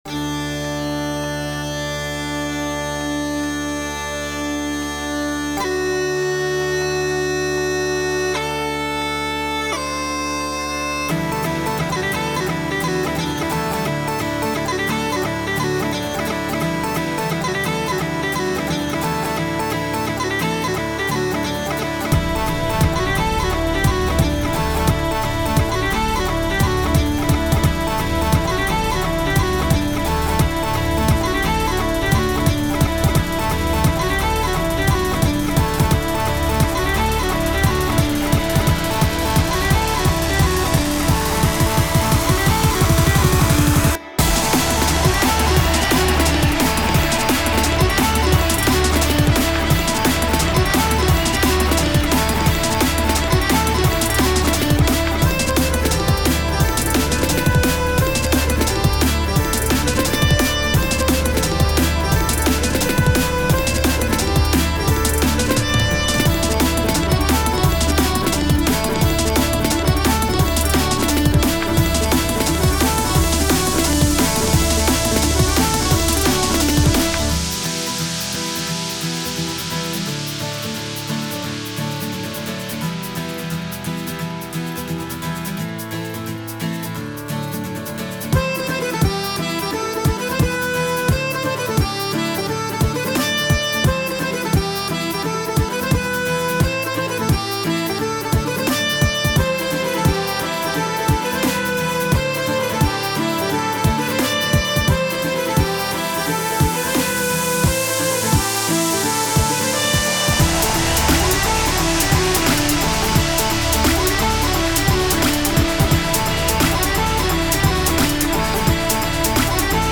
タグ: DnB EDM かっこいい 明るい/楽しい 民族音楽